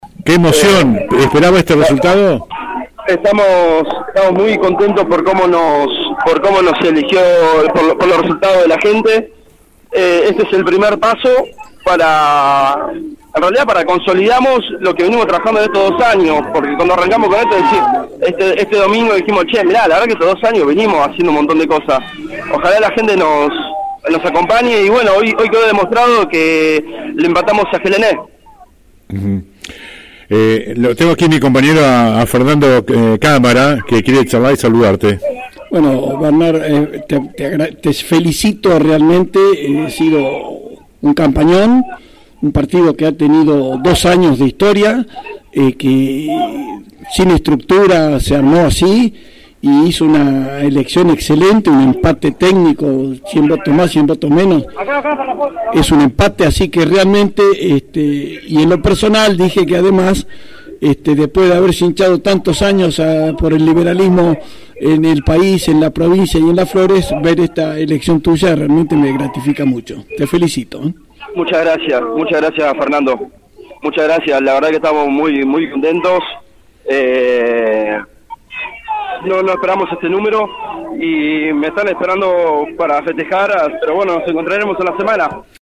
El electo concejal por LLA habló en la noche del domingo con la 91.5 desde el búnker de La Casa de la Libertad (Harosteguy 176) rodeado de un numeroso grupo del espacio mileísta. «Estamos muy contentos por la manera en la que nos eligió la gente. Esto es el primer paso de lo que comenzamos a consolidar hace dos años y eso se reflejó en las urnas. Hoy quedó demostrado que le empatamos a Gelené», expresó Lemma.